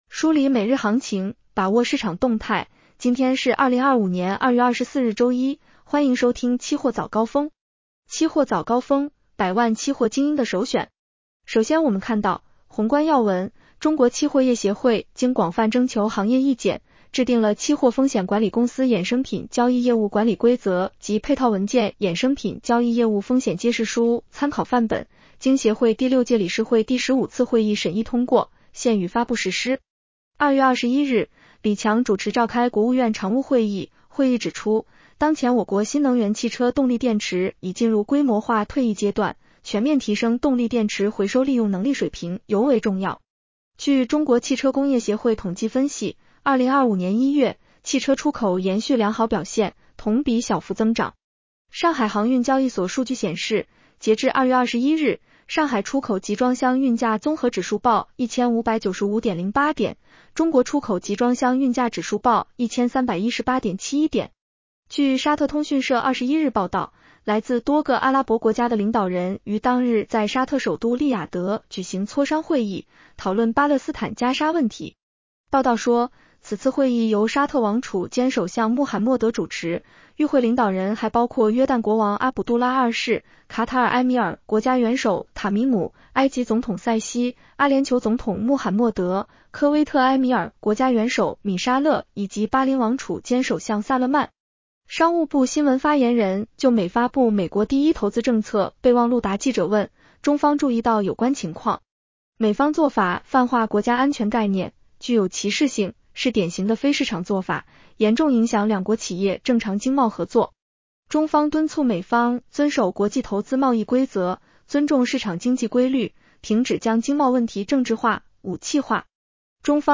期货早高峰-音频版
女声普通话版 下载mp3